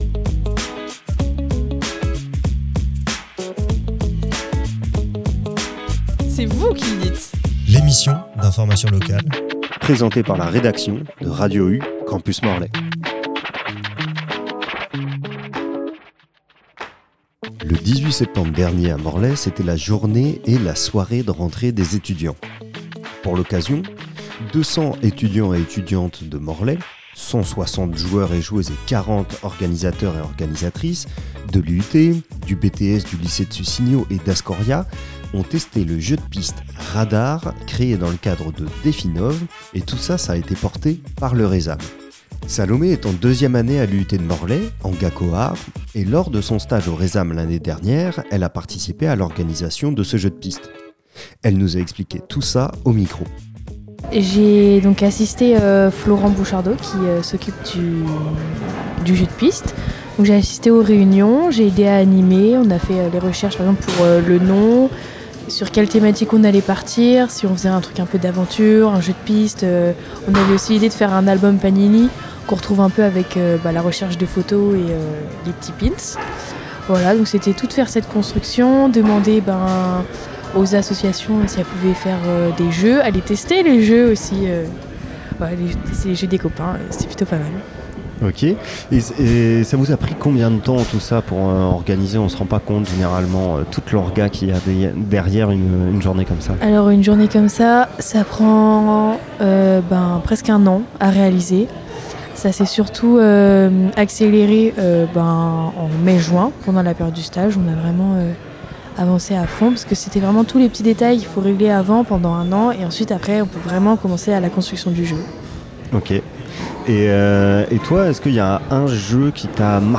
On a également reçu à nos micros les deux équipes ayant terminé aux deux premières places...